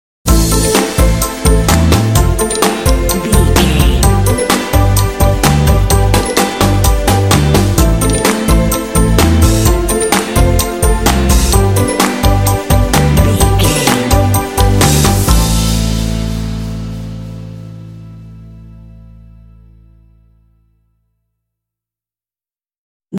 Uplifting
Aeolian/Minor
bouncy
groovy
piano
electric guitar
bass guitar
drums
strings
rock